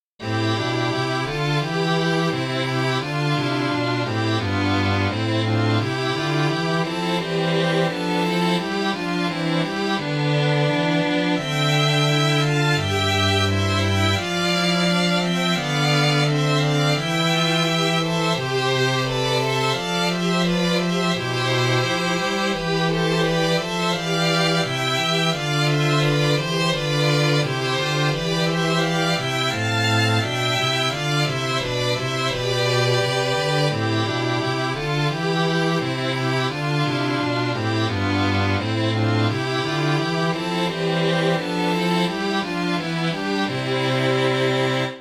弦楽合奏